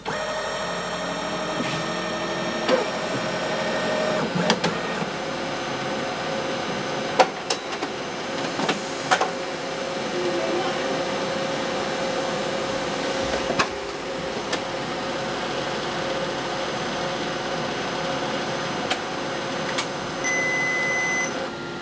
CopyMachine_5.wav